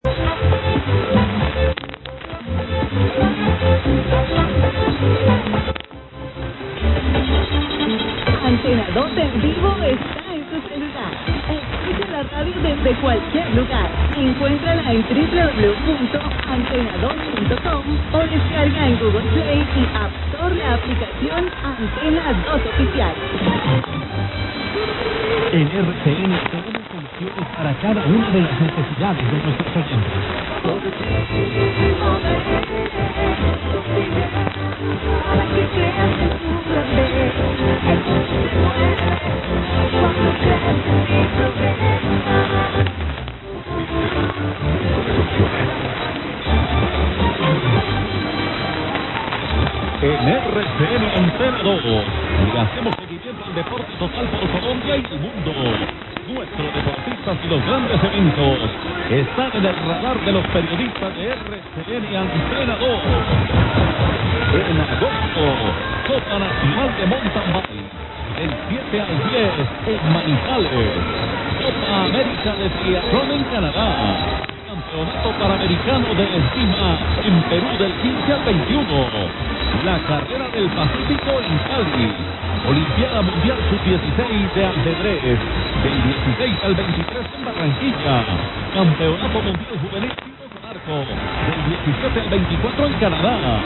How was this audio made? During a pretty good Colombia run around 0302 UTC tonight (UTC 6 AUG / EDT 5 AUG), Antena Dos on 650 was in with a ton o’bricks signal.